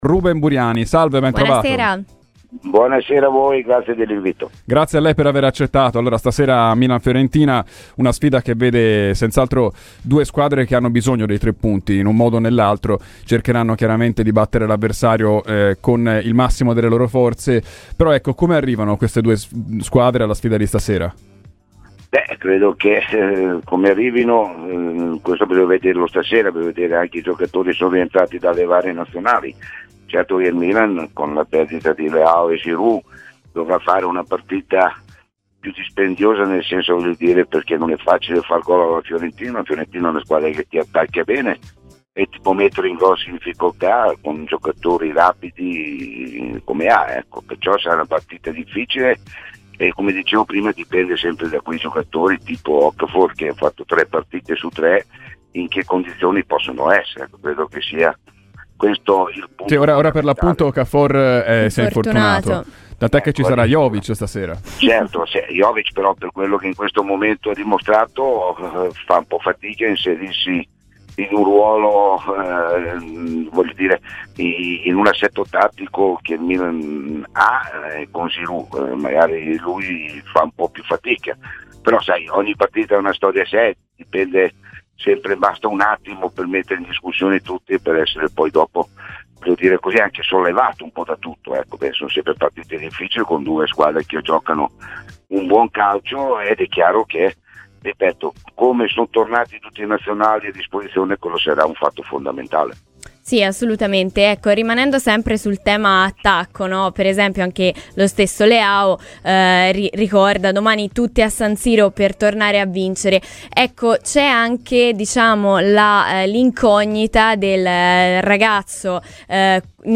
L'ex centrocampista rossonero Ruben Buriani, durante "Viola Weekend" su Radio FirenzeViola, ha dato la sua opinione verso Milan-Fiorentina di questa sera: "Il Milan senza Giroud e Leao dovrà fare una gara dispendiosa.